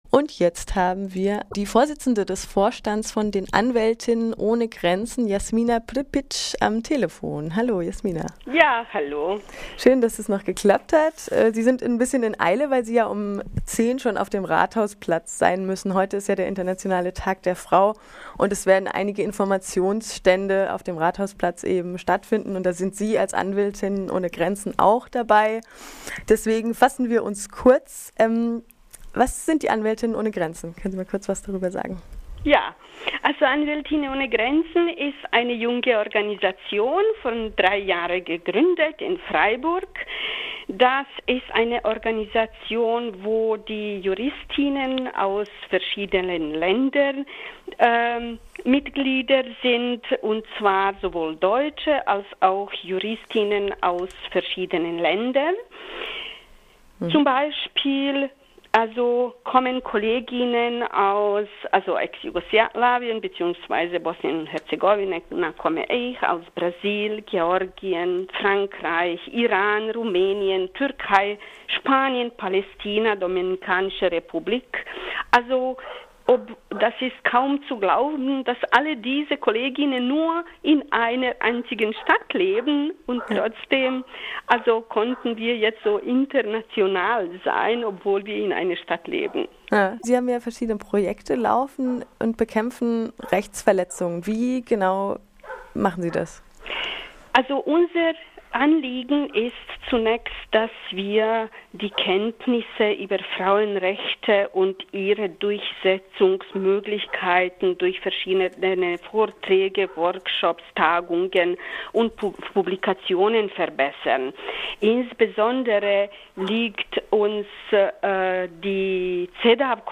Weltfrauentag ein Interview im Morgenradio. Sie sprach über die Arbeit des jungen Vereins, einem Zusammenschluß von Juristinnen mit ganz verschiedenem kulturellen Hintergrund.